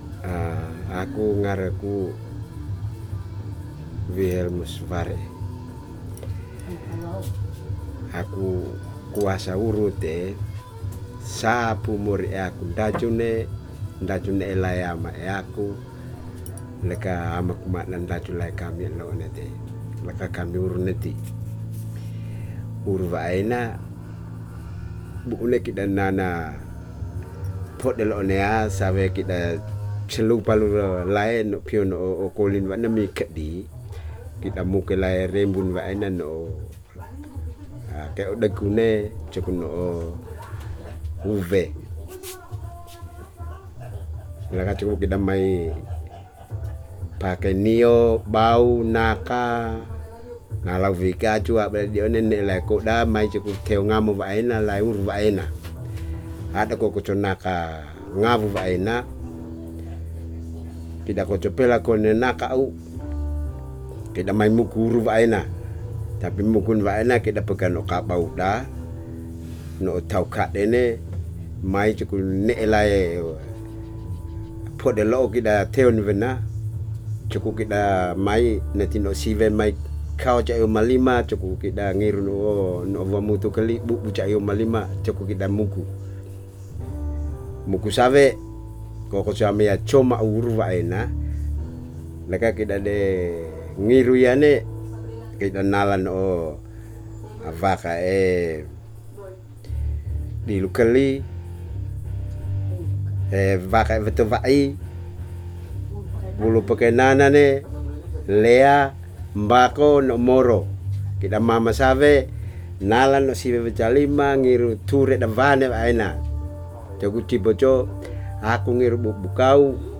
Music is heard playing in the background throughout the recording, and it actually sounds really good, coincidentally.
dc.description.regionPalu'e, Flores, Nusa Tenggara Timur, Indonesia. Recording made in kampong Habi, Woja domain.